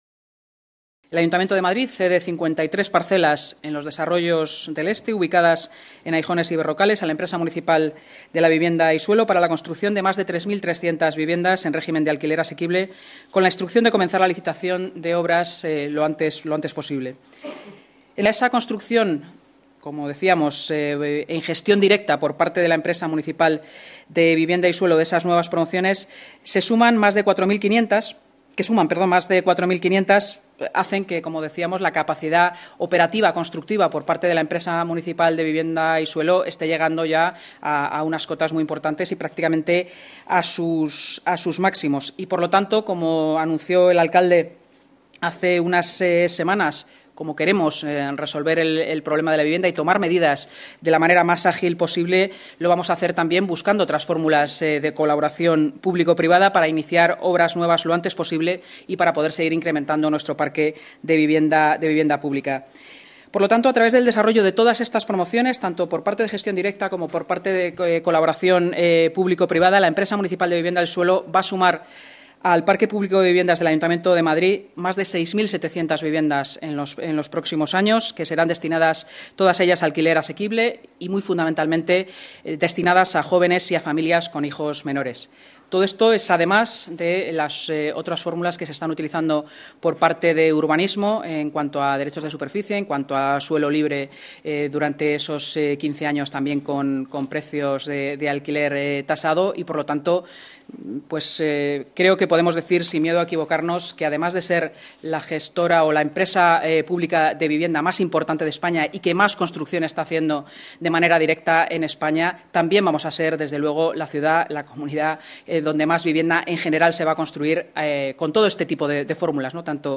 Nueva ventana:Inma Sanz, portavoz municipal